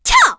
Erze-Vox_Attack1_kr.wav